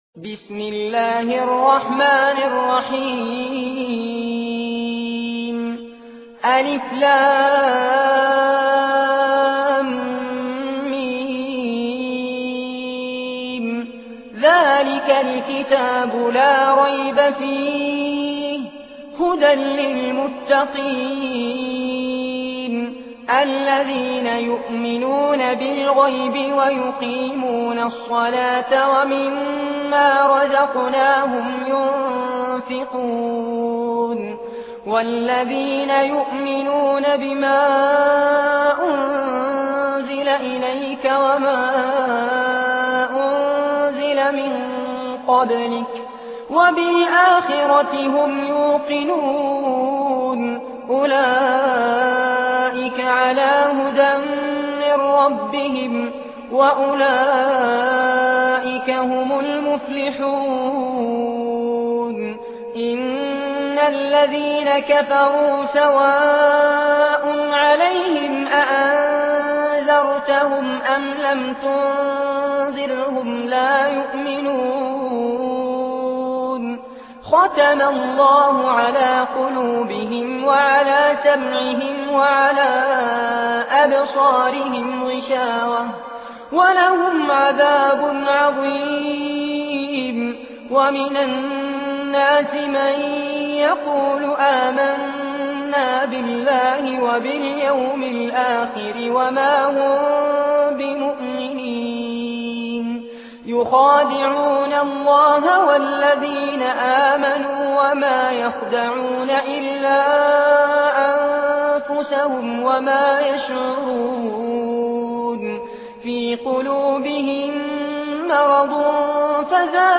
tilawat / recitation in Arabic